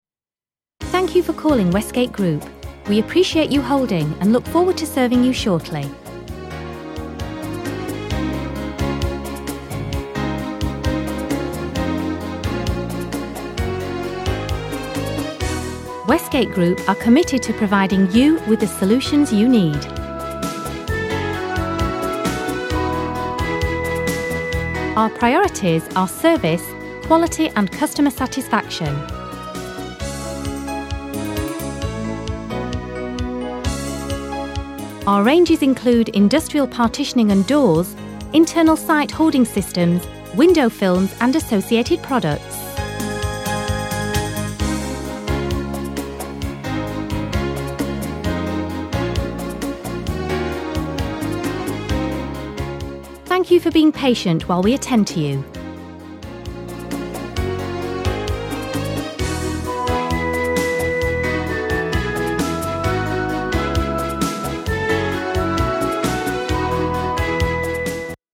britisch
Sprechprobe: Industrie (Muttersprache):